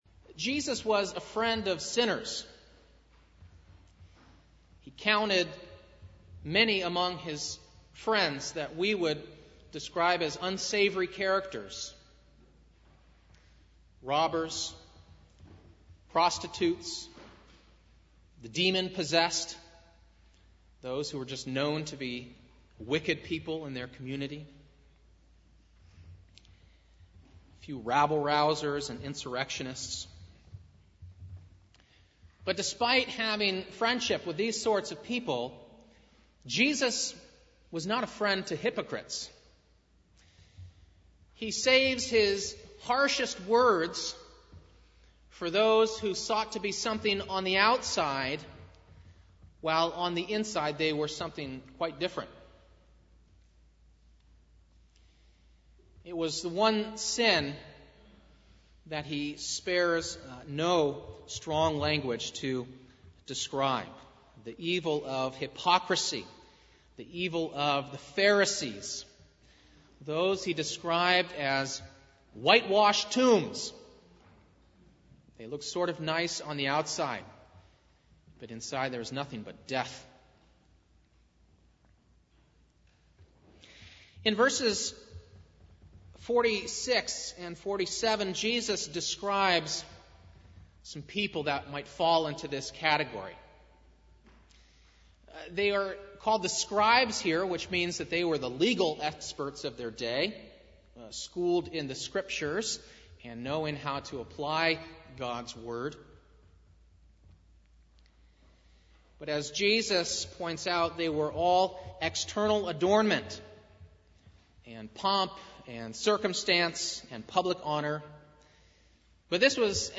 Malachi 3:6-18 Service Type: Sunday Morning « 25.